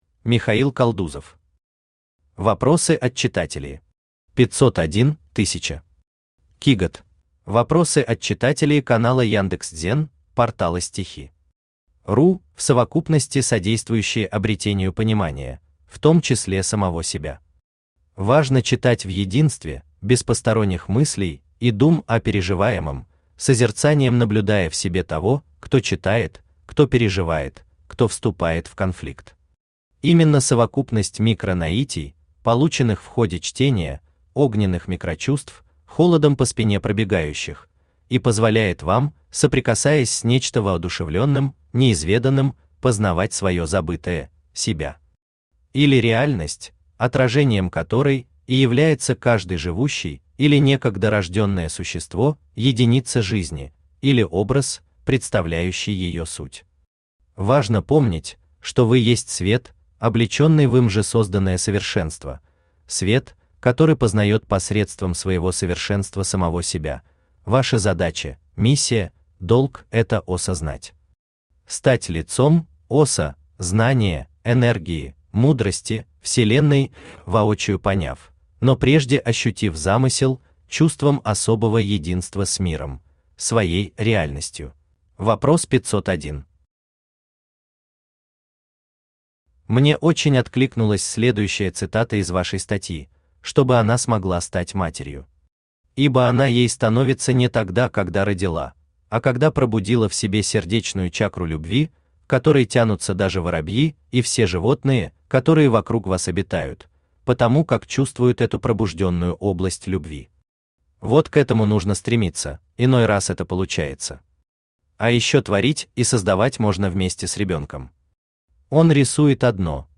Qigod Автор Михаил Константинович Калдузов Читает аудиокнигу Авточтец ЛитРес.